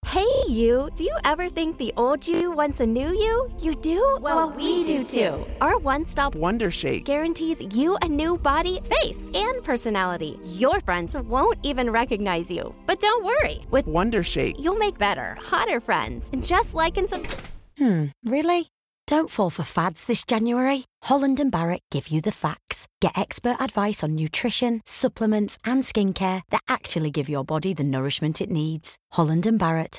Because just as you’re rolling your eyes, the voiceover cuts in: don’t fall for fads. Turn to Holland & Barrett for actual facts on nutrition, supplements and skincare.